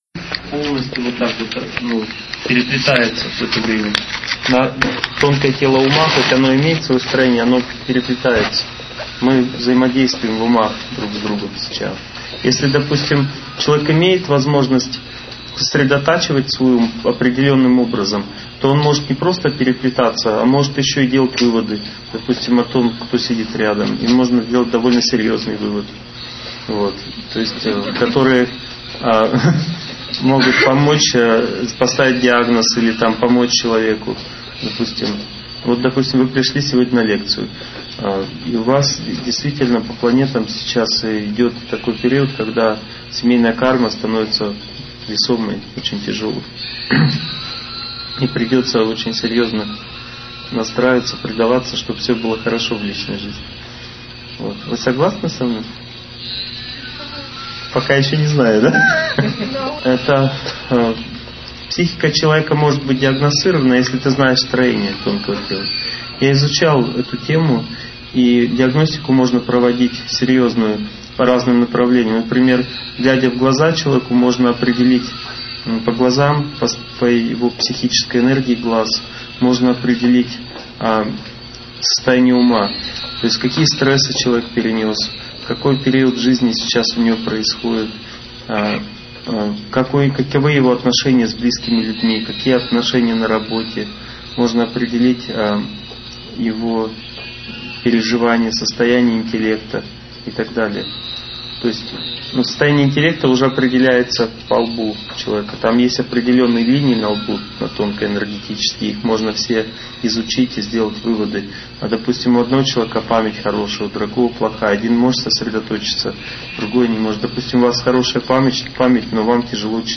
Aудиокнига Семейные узы любви. Часть 1